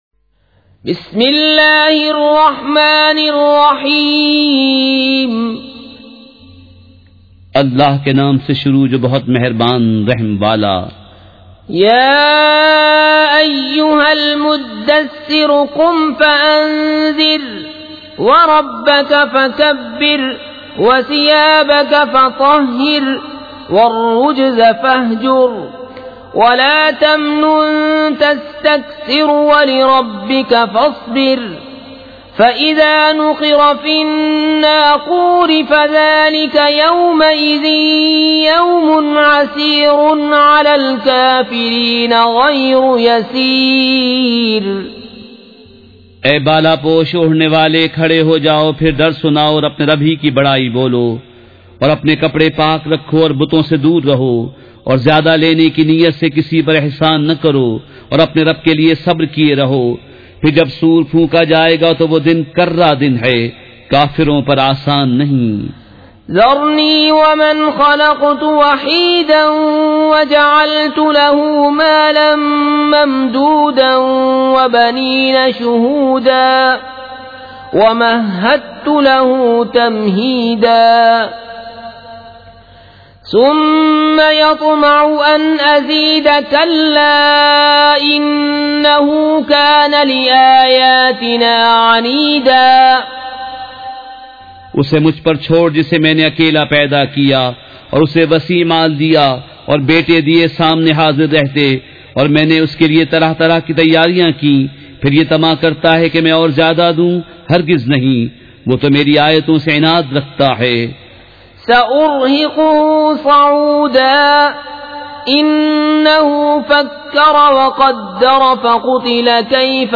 سورۃ المدثّر مع ترجمہ کنزالایمان ZiaeTaiba Audio میڈیا کی معلومات نام سورۃ المدثّر مع ترجمہ کنزالایمان موضوع تلاوت آواز دیگر زبان عربی کل نتائج 1537 قسم آڈیو ڈاؤن لوڈ MP 3 ڈاؤن لوڈ MP 4 متعلقہ تجویزوآراء